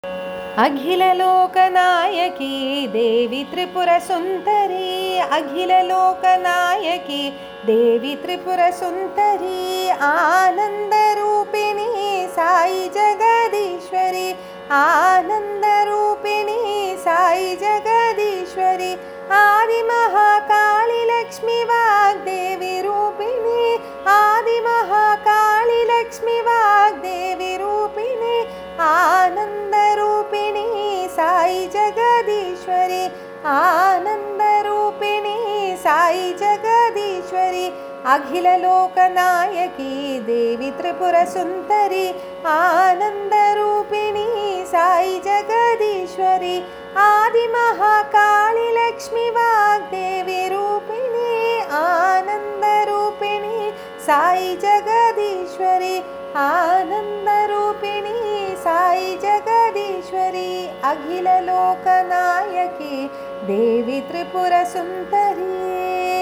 1. Devotional Songs
Hamsadhwani
8 Beat / Keherwa / Adi
Medium Fast
1 Pancham / C
5 Pancham / G